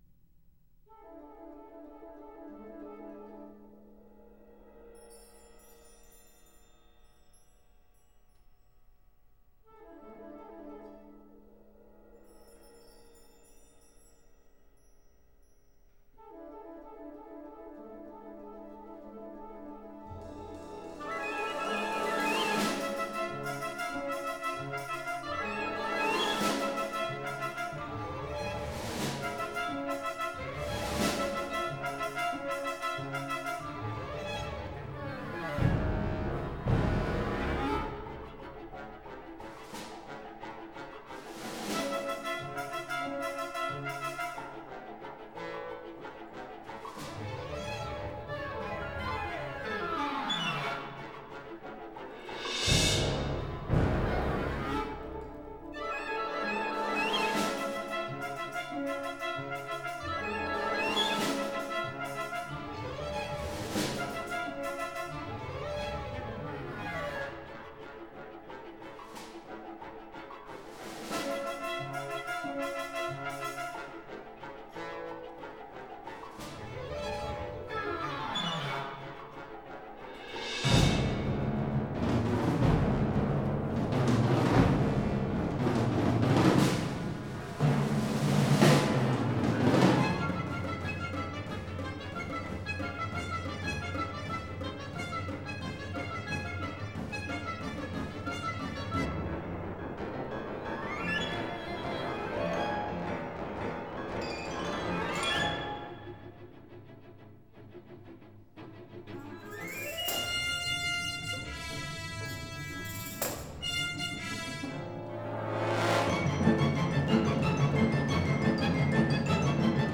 Recorded in 1992 at the Centennial Concert Hall in Winnipeg